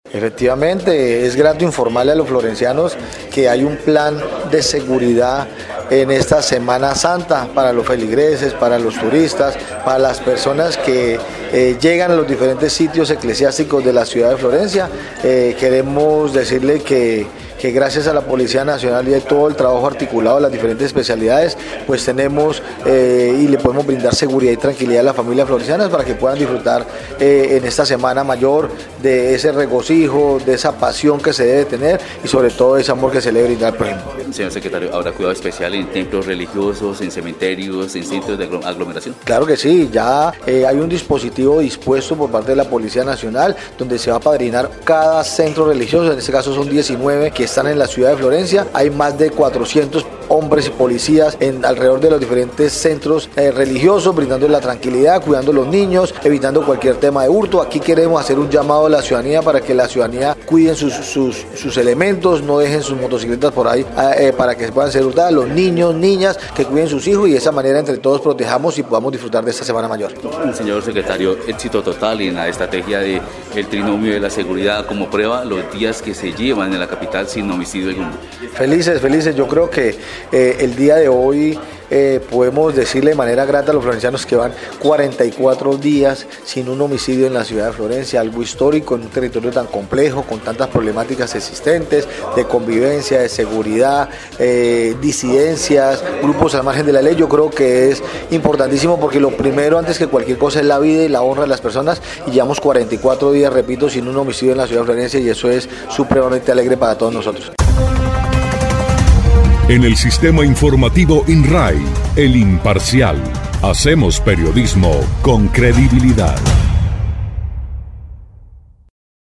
Carlos Mora Trujillo, secretario de gobierno municipal, explicó que, se contará con un componente especial dedicado a la vigilancia de cementerios, con el objetivo de prevenir actividades satánicas y de sacrificios en estos lugares sagrados.